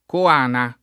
coana [ ko # na ]